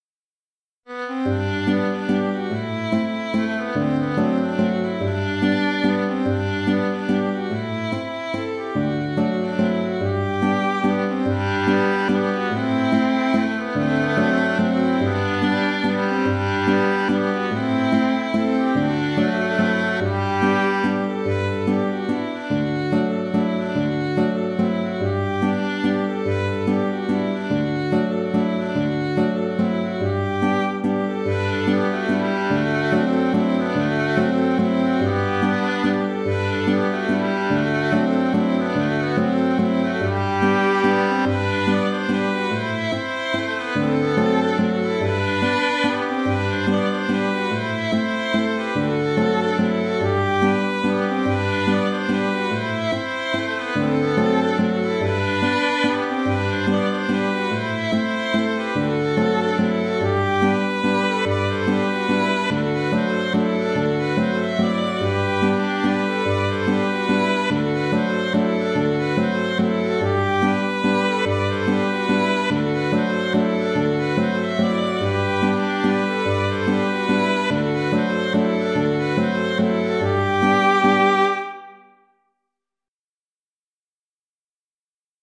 Mazurka du Pays Gallo (Mazurka) - Musique bretonne
» Je propose là encore deux contrechants, simples, à alterner selon le choix des musiciens.. Auteur : Trad. Bretagne.